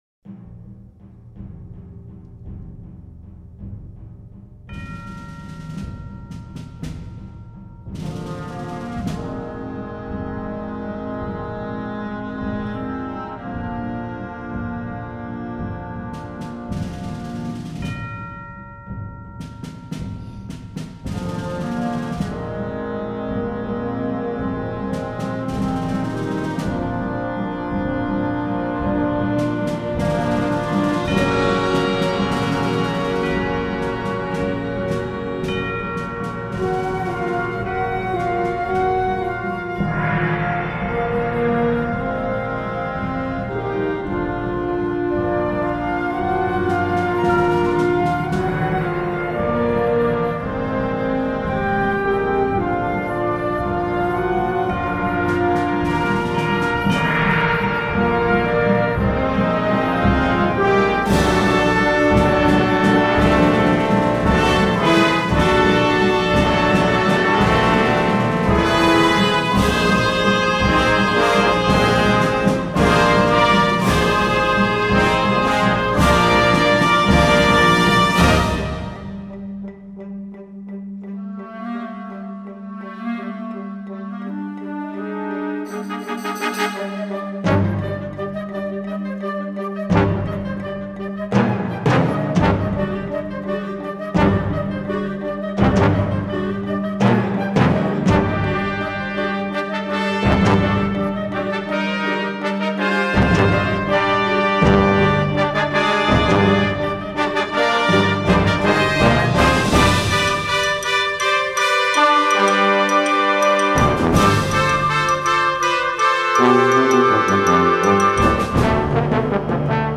The final burst of energy portrays the daring escape.